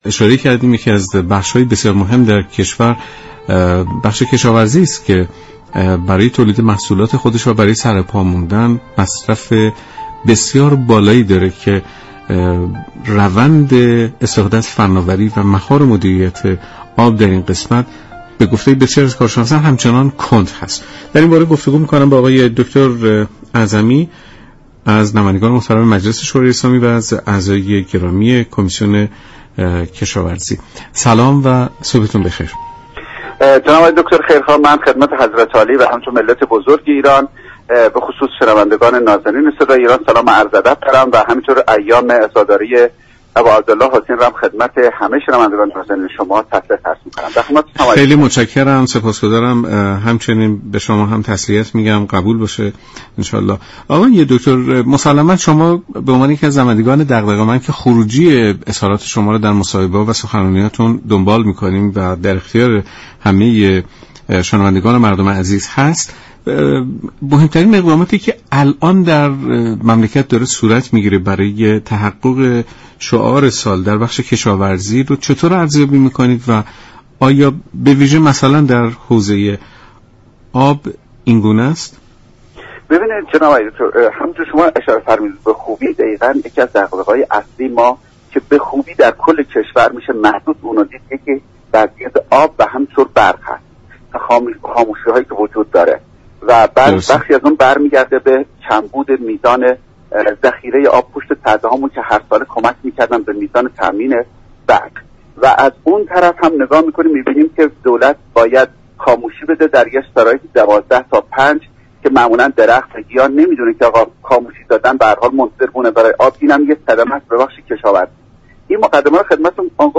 به گزارش شبكه رادیویی ایران، «ذبیح اله اعظمی» عضو كمیسیون كشاورزی،آب و منابع طبیعی مجلس در برنامه «سلام صبح بخیر» رادیو ایران از نقش شركت های دانش بنیان در مدیریت آب صحبت كرد و گفت: شركت های دانش بنیان با ارائه راهكارهای درست می توانند در مدیریت آب نقش مهم و اساسی ایفا كنند.